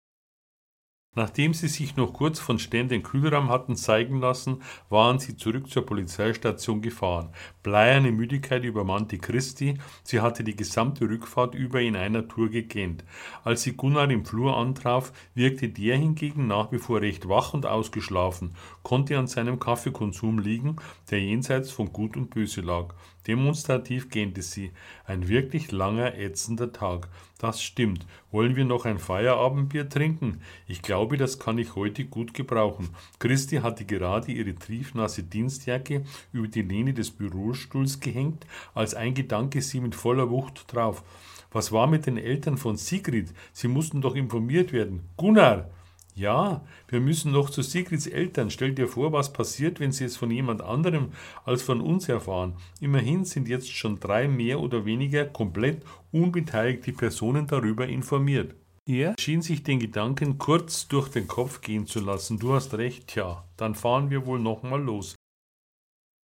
DAISY-Hörbuch